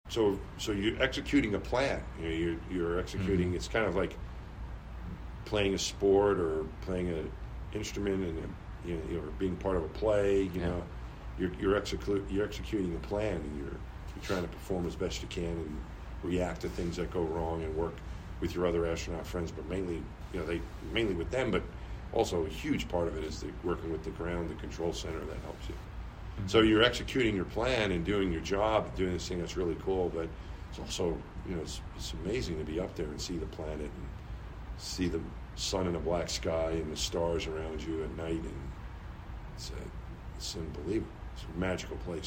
A Former NASA Astronaut and space walker walked onto the stage at the Tony’s Pizza Event Center. to speak at the Salina Area Chamber of Commerce’s annual banquet meeting.
Tonys-Pizza-Events-Center.mp3